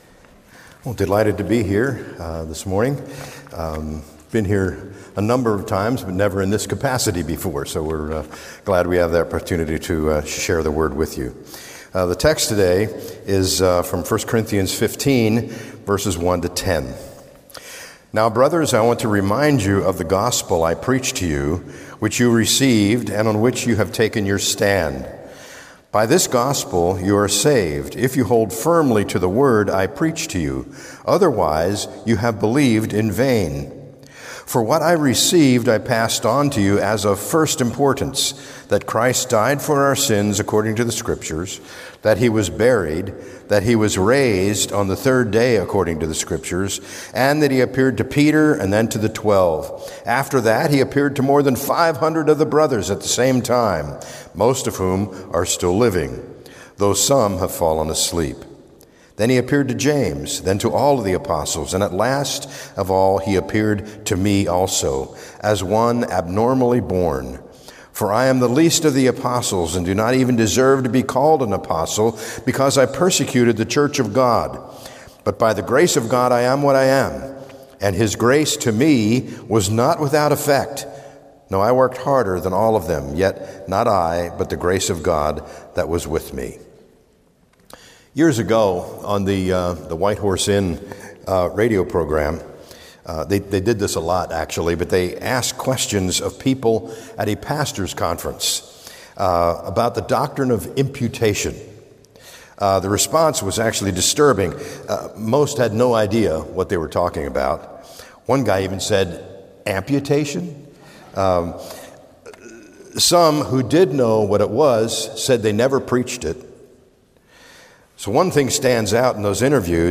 From Series: "Guest Sermons"